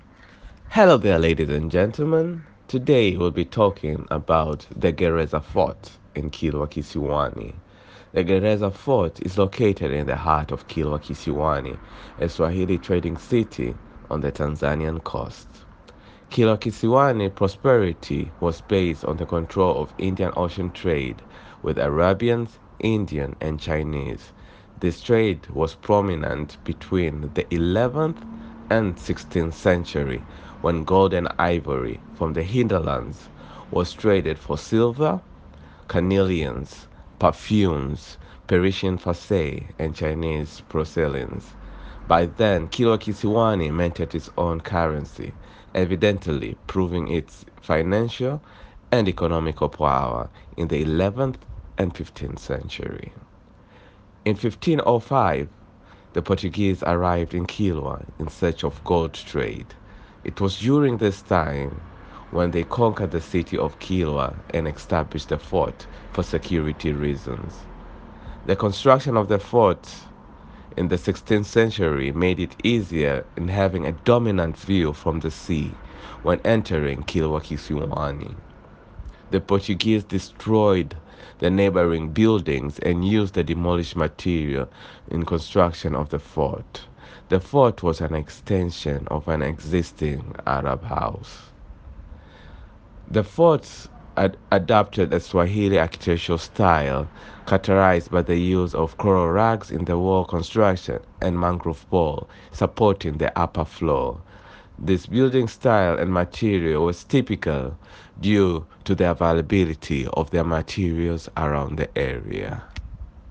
Audio description of Gereza Fort